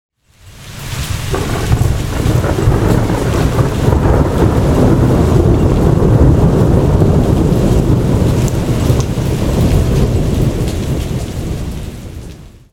Атмосфера грозы, дождя 01 нояб. 2023 г.
Звук дождя, грозы